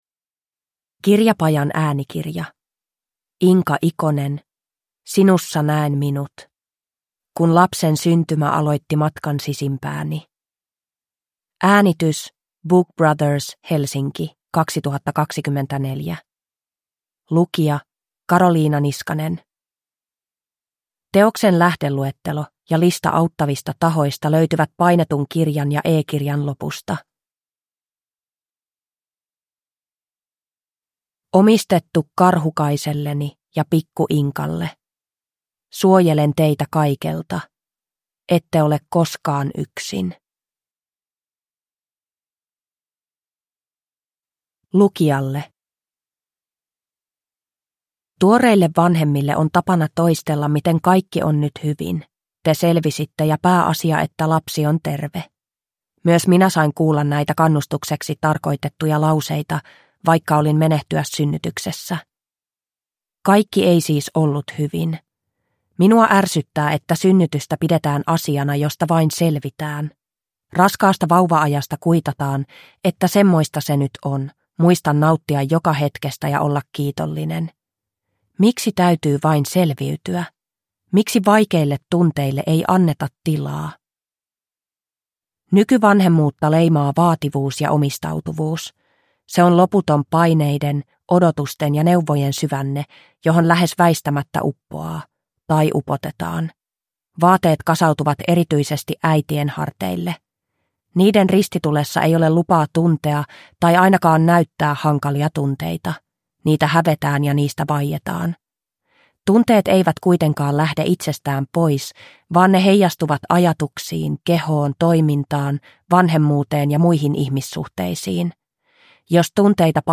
Sinussa näen minut – Ljudbok